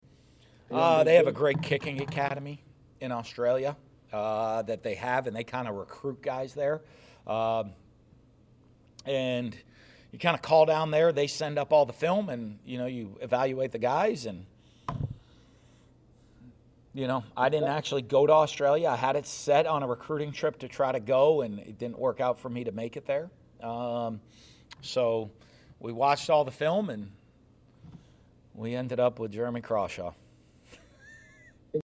Gators Head Coach Dan Mullen spoke to the media Monday ahead of Florida’s trip to Baton Rouge.